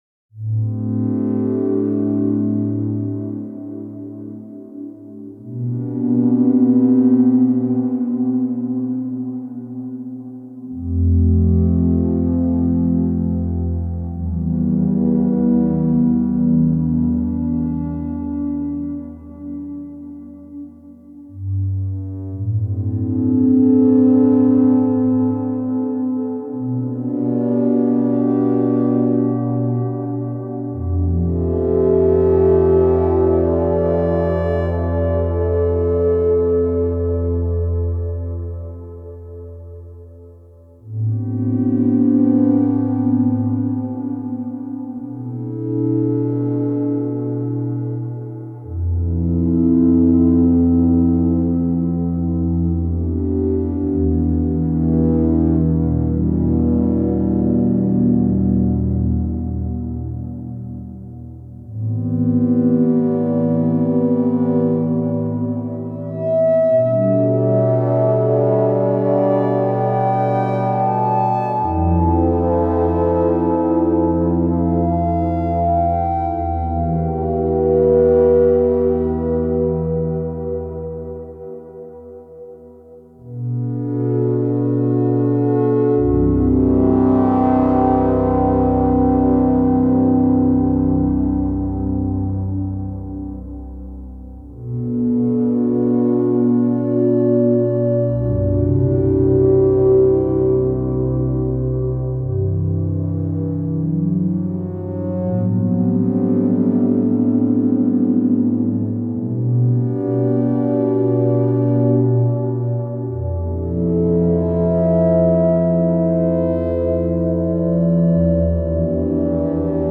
I do feel this piece enveloping like a big warm hug.